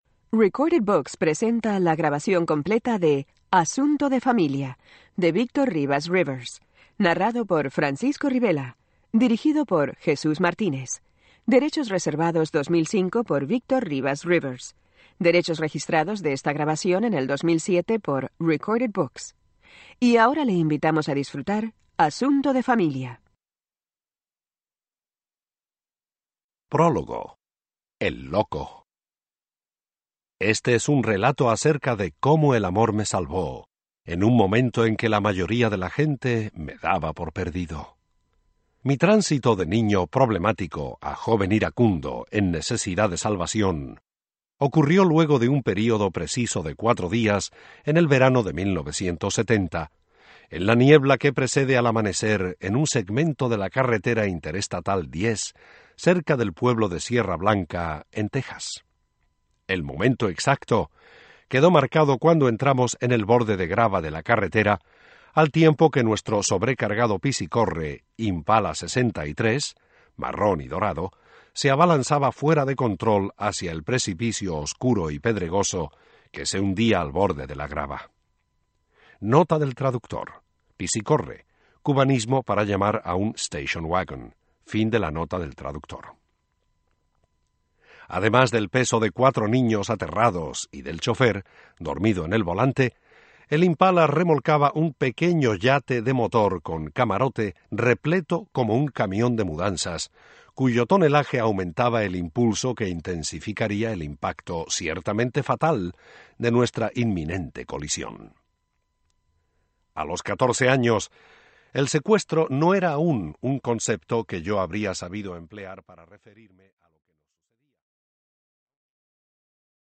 Audiobook - Asunto de familia